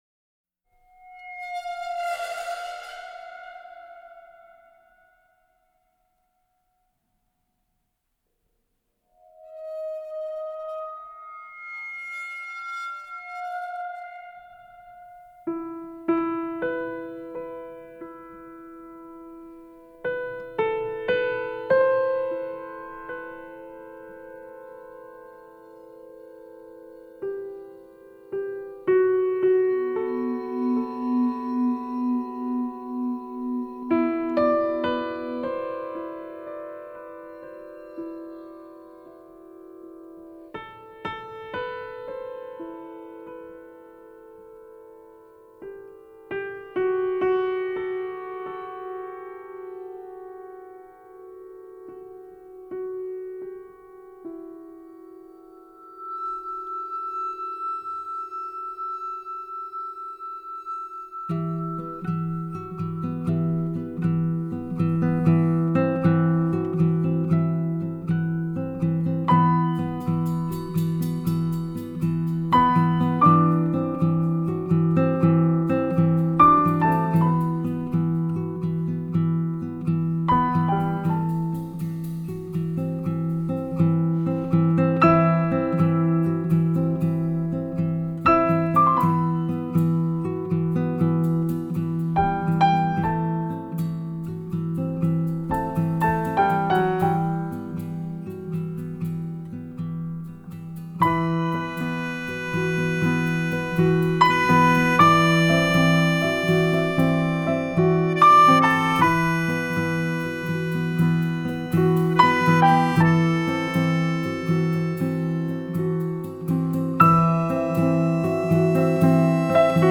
★ 滿載拉丁熱情、爵士、探戈與古典齊聚一堂的音樂小小冒險！
音質清澈通透、密度超高！
長笛、拉丁樂器的巧妙運用，音樂更顯色彩繽紛、清新雋永
flute,vibrandoneon
saxophone
guitar,mandolin
piano,accordion
double bass
percussion,vibraphone,bells
violoncello